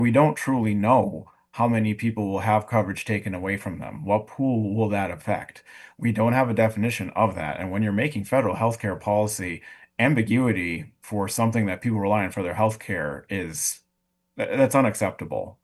A group called the “Hands Off Medicaid” Coalition launched with a news conference Thursday morning.